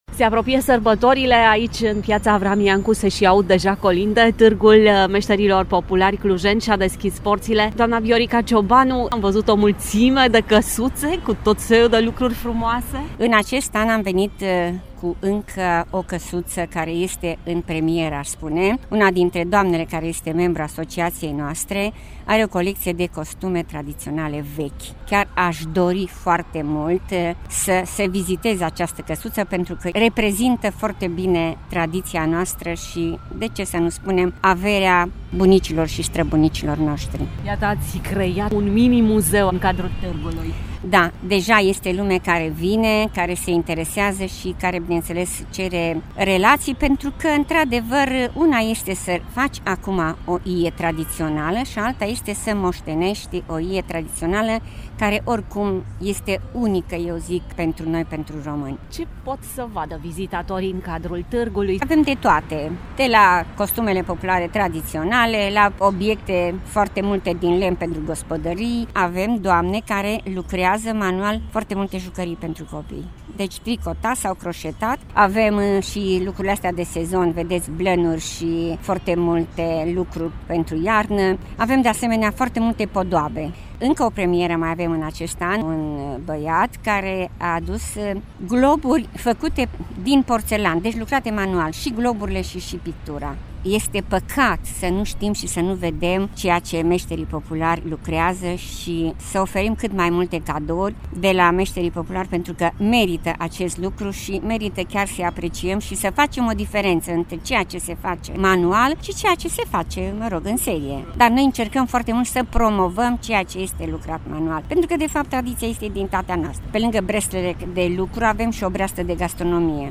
Târgul de Crăciun ”Tradiții de Iarnă” organizat de Asociația Meșterilor Populari Clujeni și-a deschis porțile în Piața Avram Iancu.